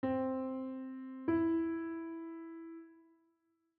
Major 3rd
C-Major-Thid-Interval-S1.wav